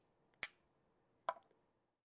Klauzury 2017 » cinkani dvou hrnicku o sebe
描述：cinkání dvou hrníčků na čaj o sebe
标签： tinkling cups tea
声道立体声